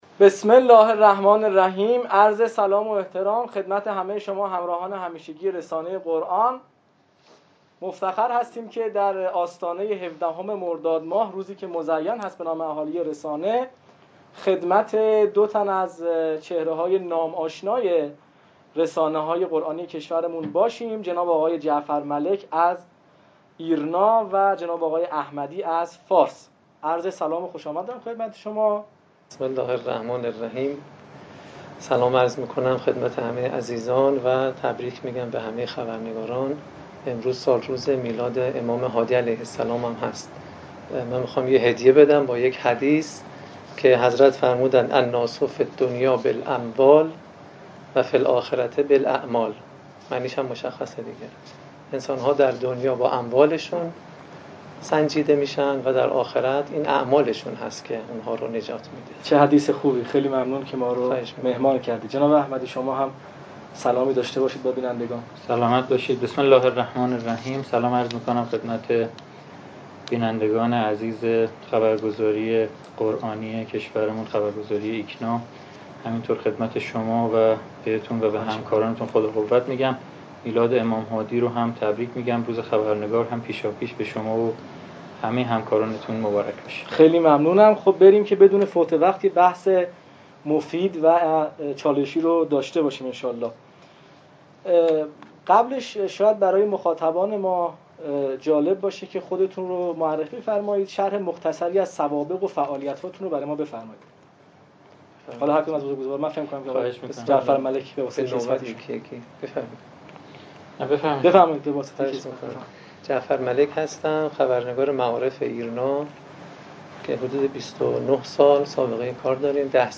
تعریف خبر و خبرنگار قرآنی، نقد و بررسی عملکرد رسانه‌های قرآنی در شکل کار و محتواهای ارائه شده و دلیل افت رویدادهای قرآنی در وضعیت کرونا در میزگرد «رسانه و قرآن» با حضور دو خبرنگار حوزه قرآن و معارف بررسی شد.